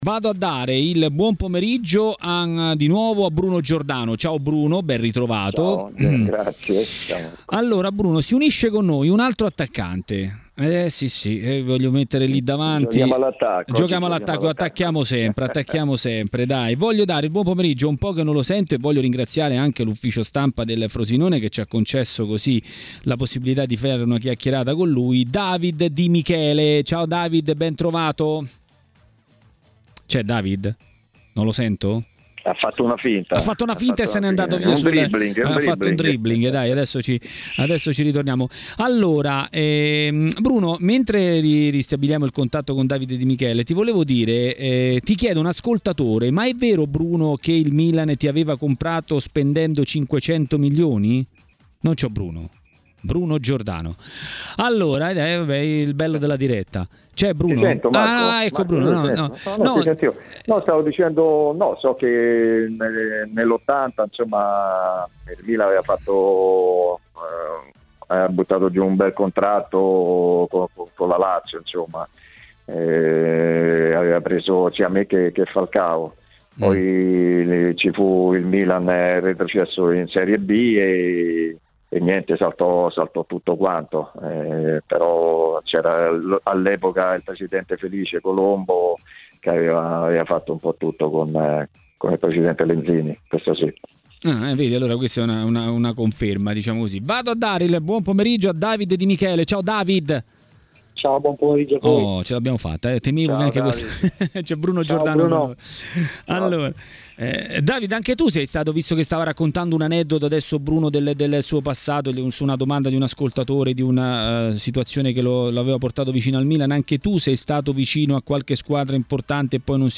A dire la sua sulla Serie A è intervenuto a TMW Radio, durante Maracanà, l'ex calciatore e tecnico David Di Michele.